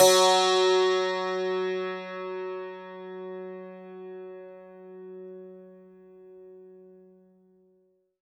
52-str02-sant-f#2.wav